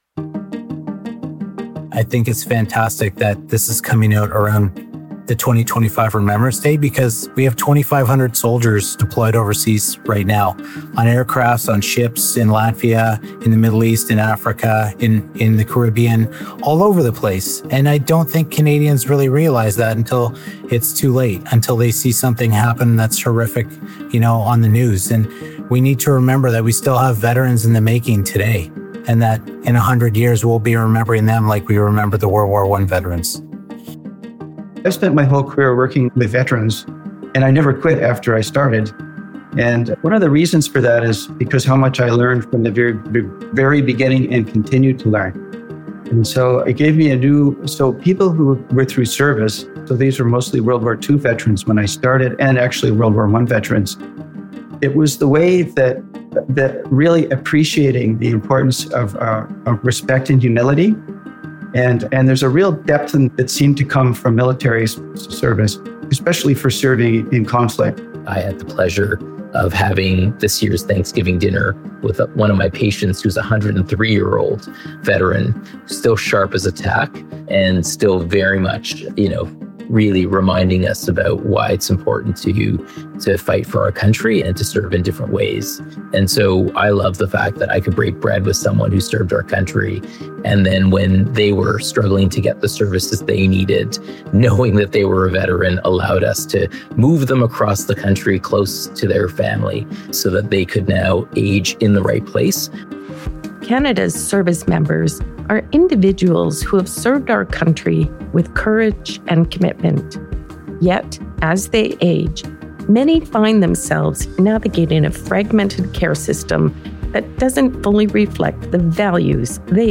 in lively discussions with national and international experts on ageism and how to reimagine seniors’ care.